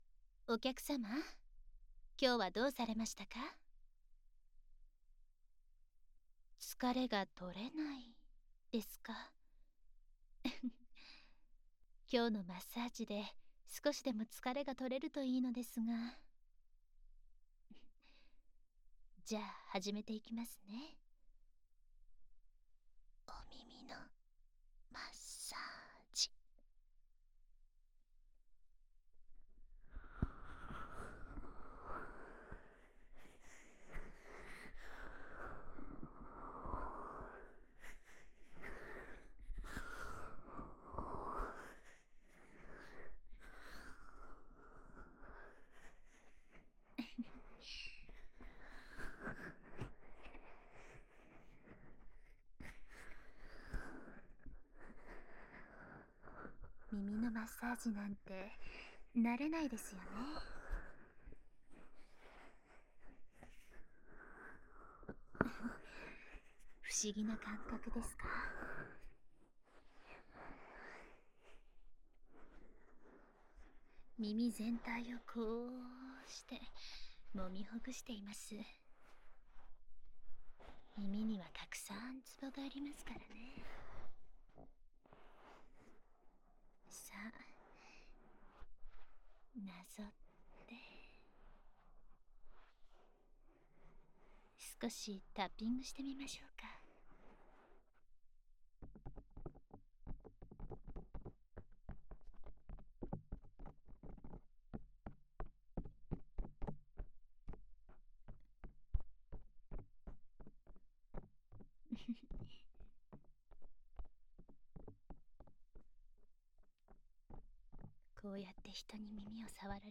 环绕音 ASMR 舔耳